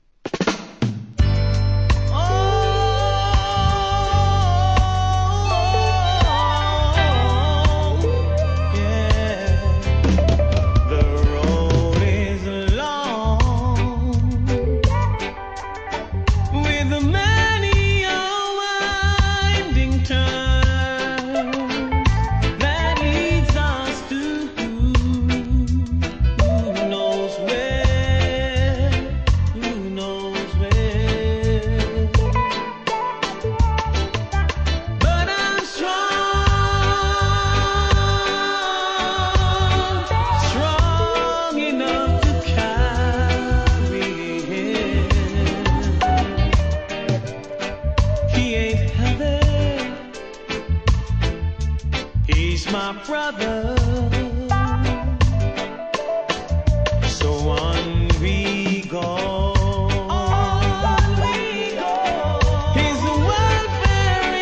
REGGAE
洗練されたミディアム・トラックの数々にソウルフルなヴォーカルで歌い上げた